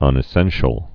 (ŭnĭ-sĕnshəl)